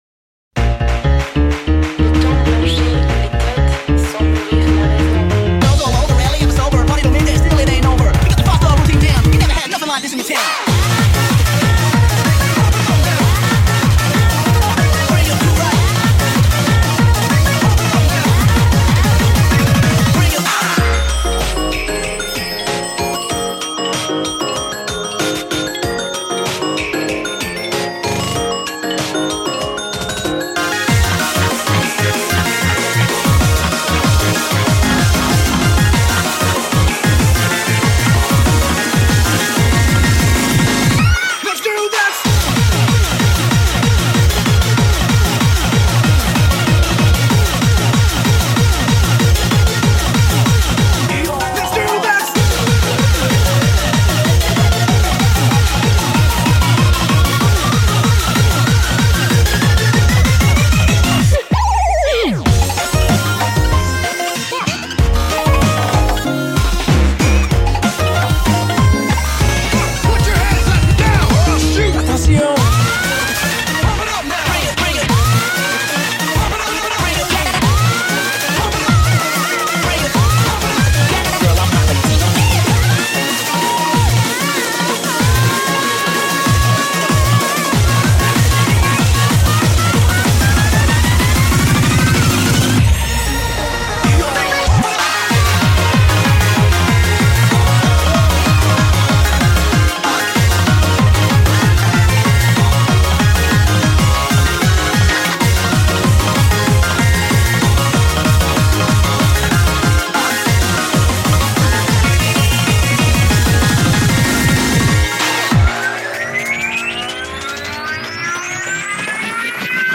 BPM190
Audio QualityPerfect (Low Quality)